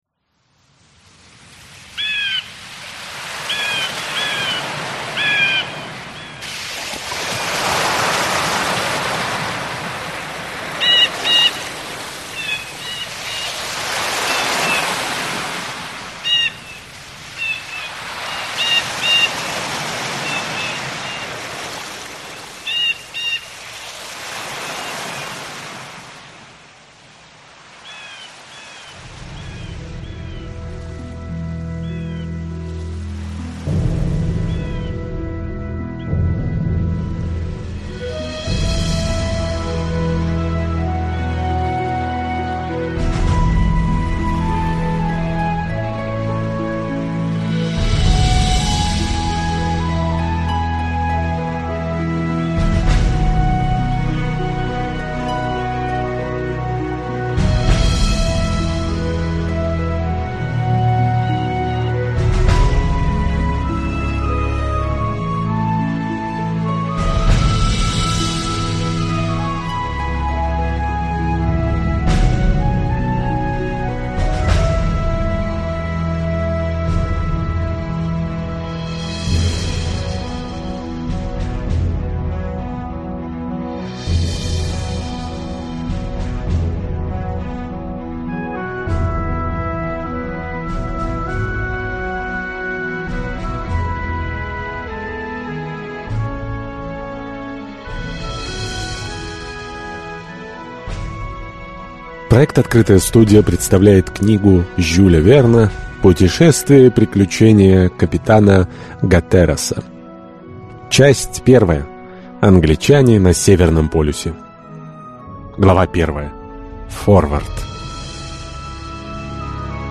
Аудиокнига Путешествие и приключения капитана Гаттераса | Библиотека аудиокниг